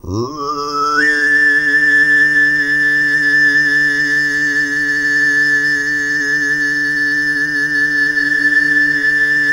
TUV1 DRONE05.wav